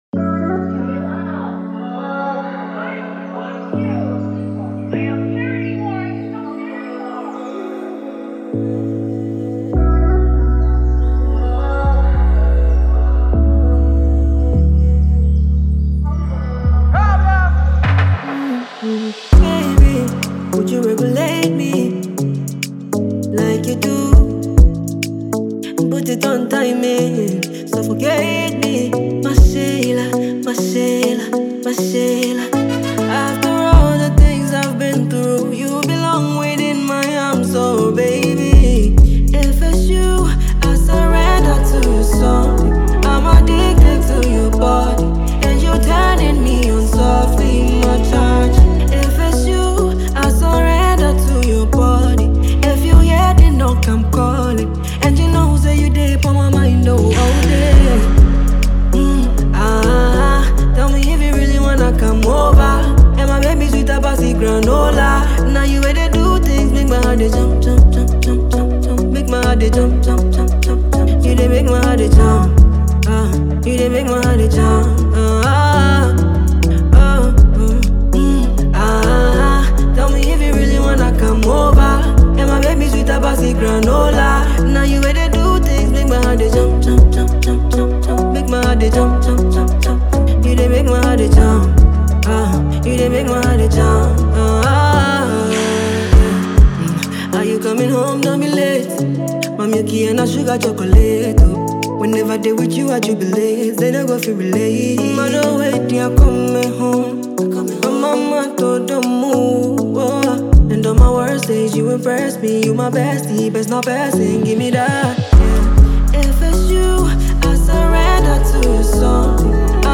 With its infectious melody and emotional delivery